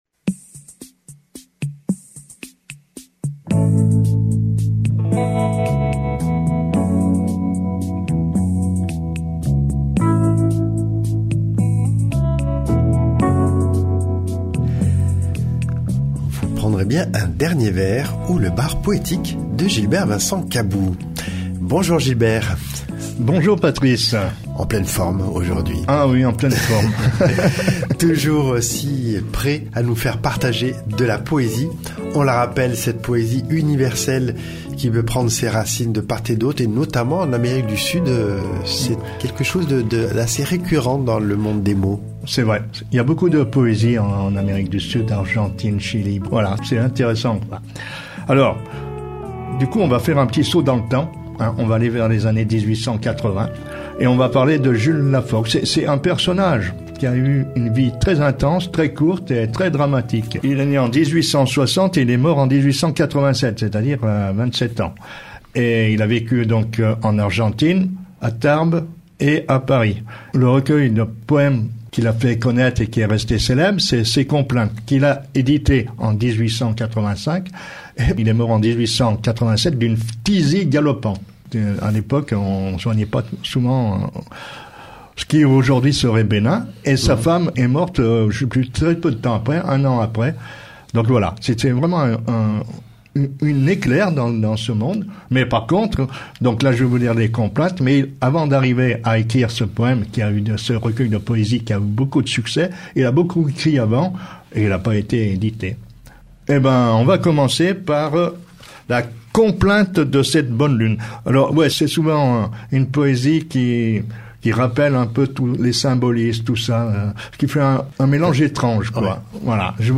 Au programme : lecture d’un poème par semaine accompagnée d’une petite biographie de l’auteur ou l’autrice.